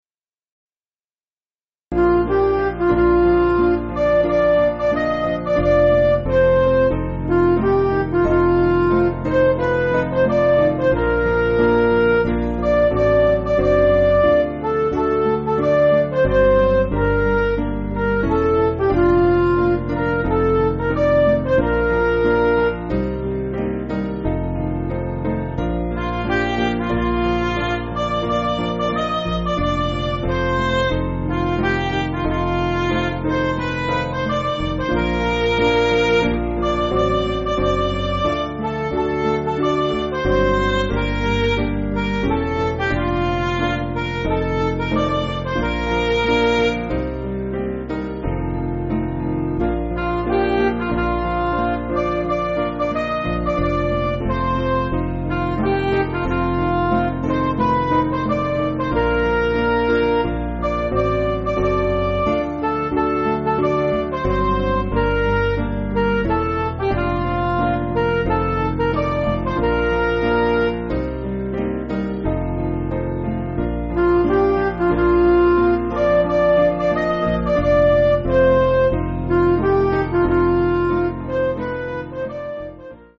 Liturgical Music
Piano & Instrumental